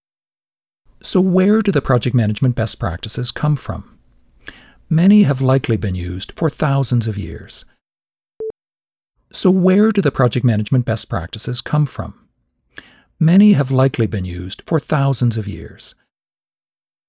Better narration, needs tuning?
Needs a bit more de-essing.
The pseudo-stereo effect is a bit too strong IMO.